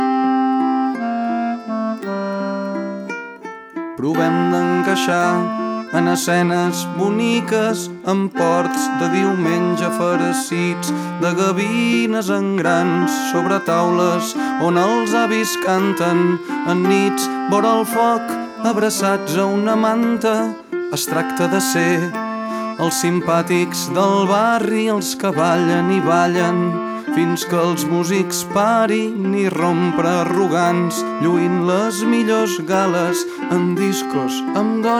Жанр: Поп / Рок / Фолк-рок